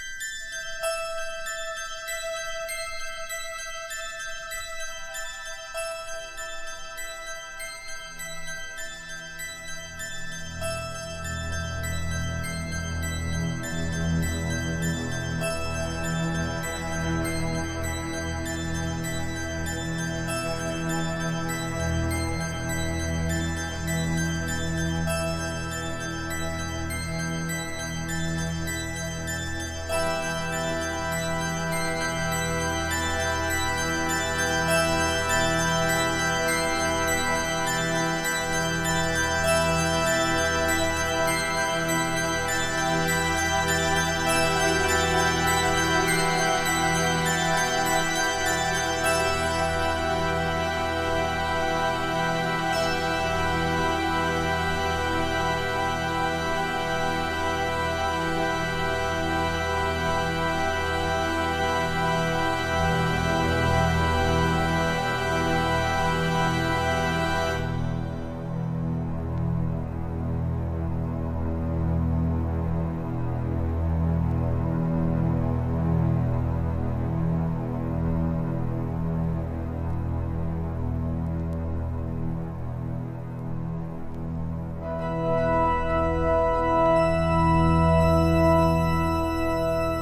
イタロ～シンセ・ディスコを楽しめる作品